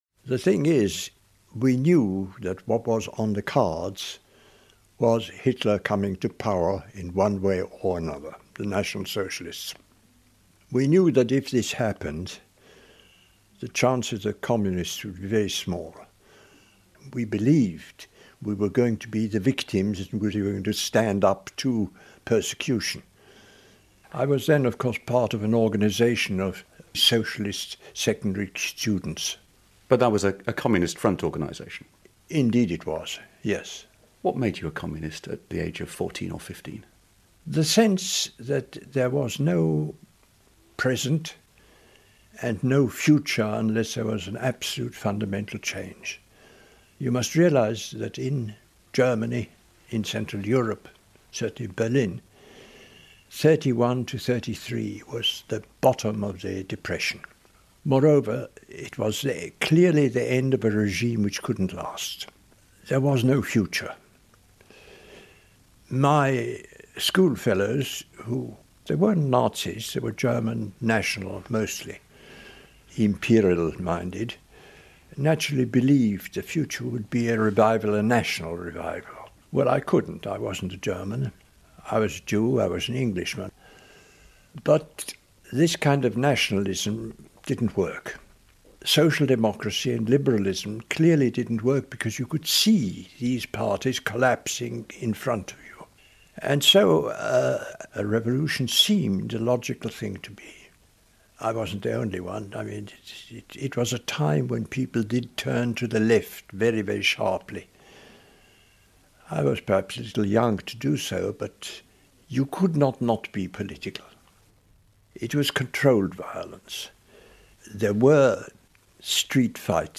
British historian Eric Hobsbawm - who passed away today - remembering his youth in Hitler's Berlin. BBC World Service